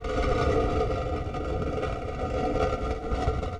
st_cretescrape.wav